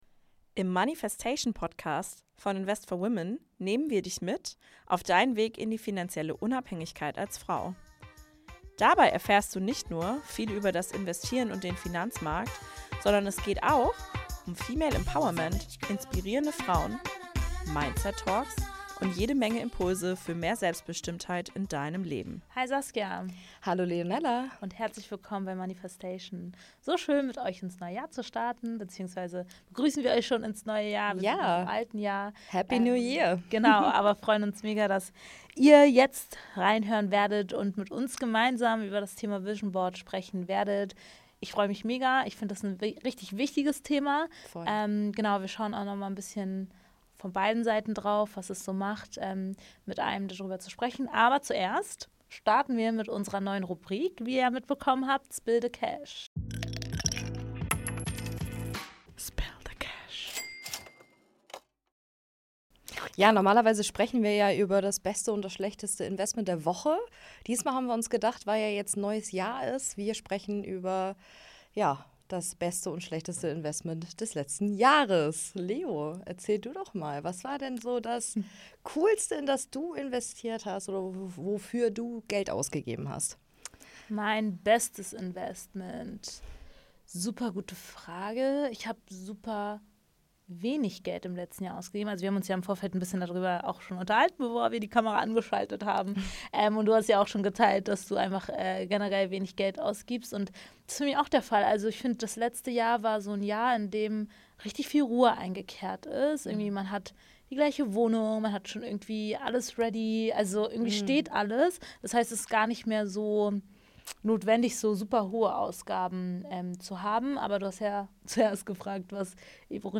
Diese Folge ist ruhig, ehrlich und ein Reminder dafür, dass finanzielle Ziele kein Stressprojekt sein müssen – sondern ein Prozess, der sich nach dir anfühlen darf.